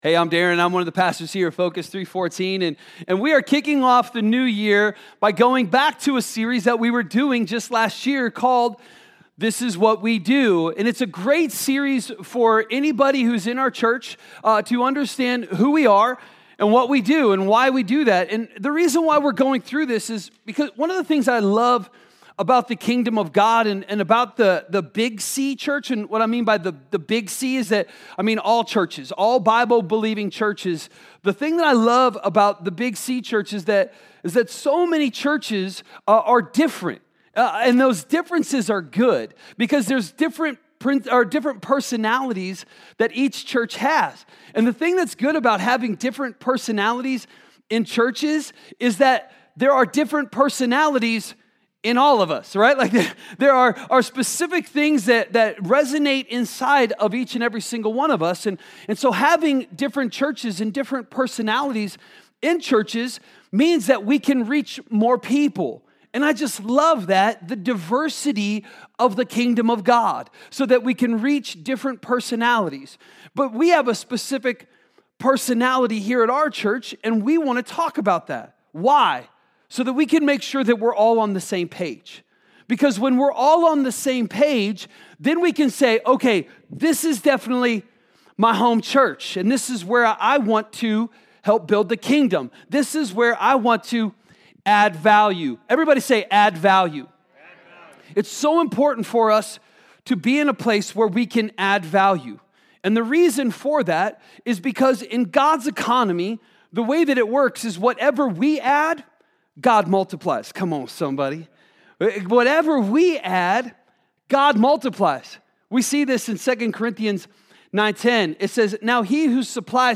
A message from the series "This Is What We Do."